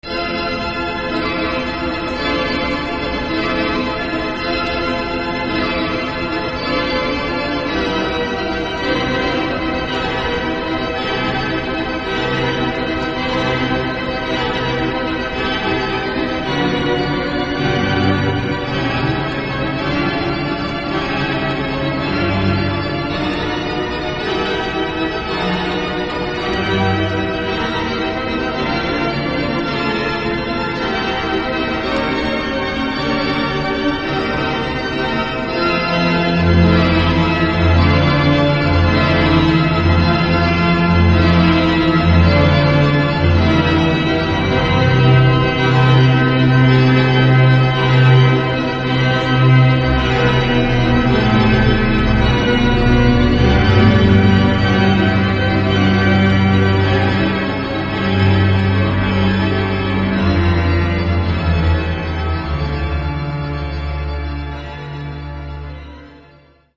durante el ensayo para su Concierto de Órgano en la Catedral de Plasencia
Pequeño fragmento audio del concierto: Toccata de Charles M. Widor